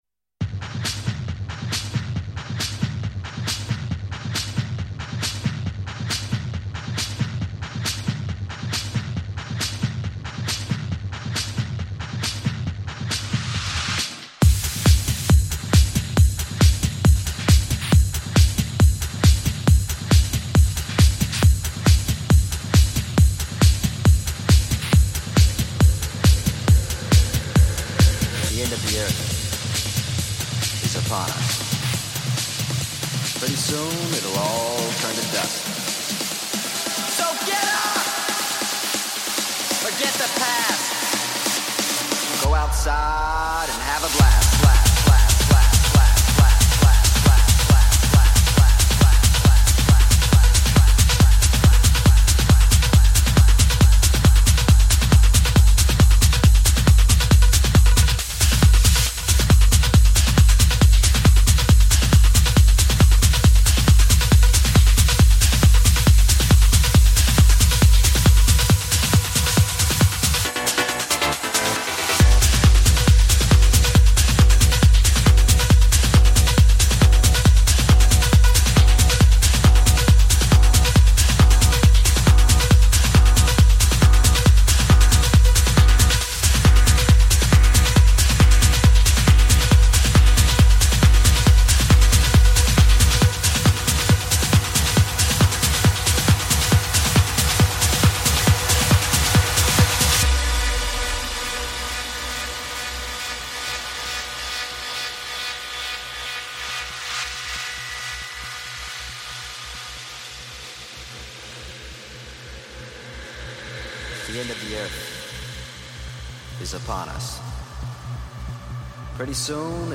Also find other EDM
Liveset/DJ mix